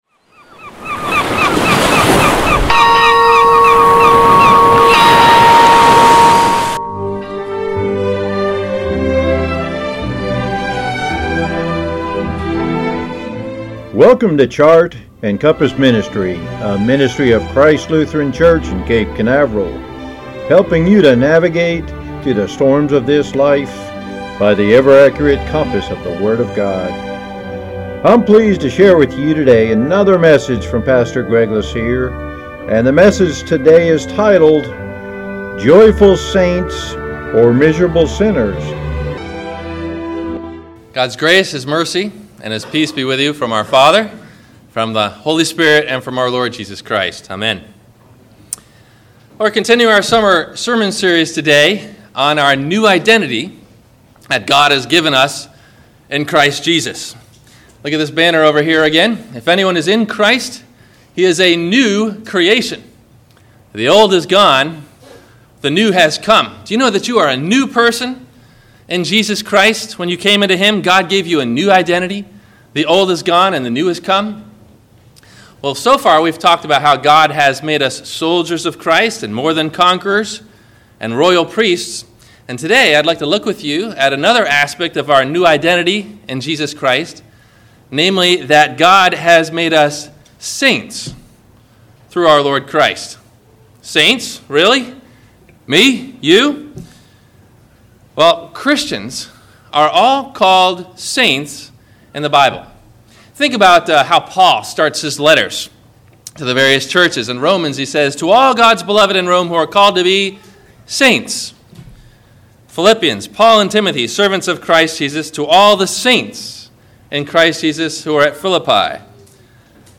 Joyful Saints or Miserable Sinners? – WMIE Radio Sermon – July 20 2020